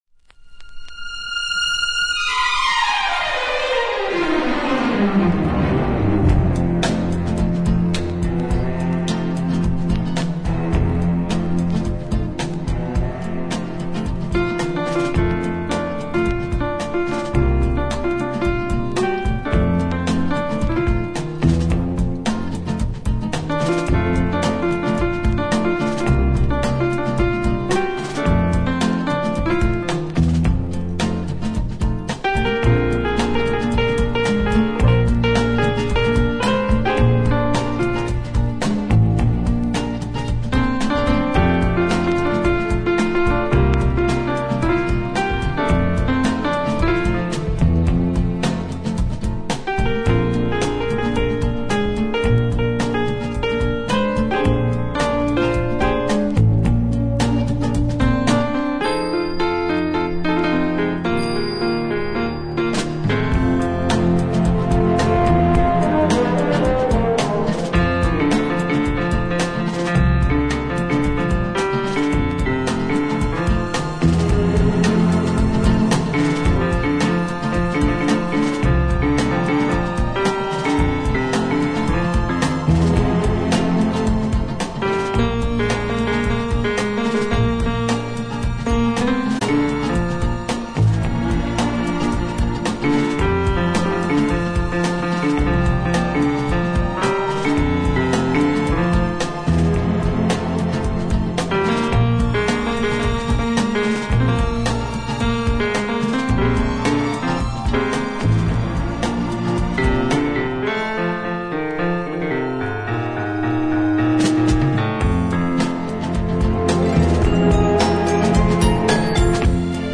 two pianos